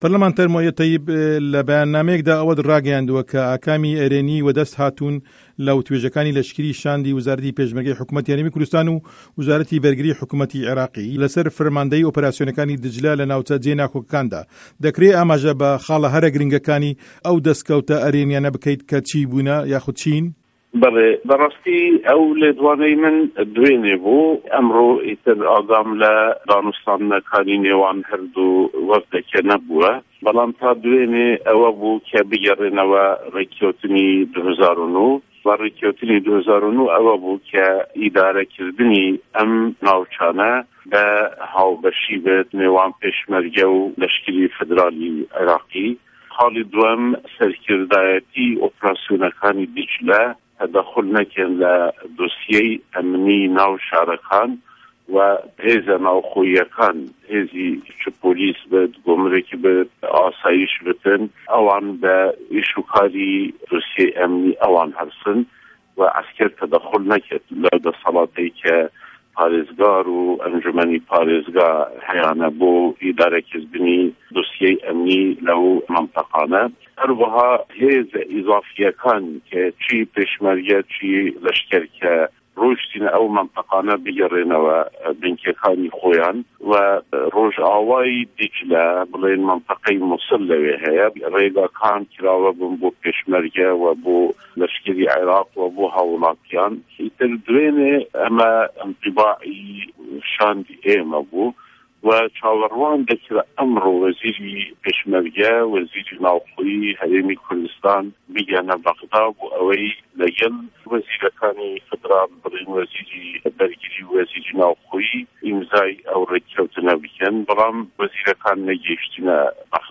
وتووێژی موئه‌یه‌د ته‌یب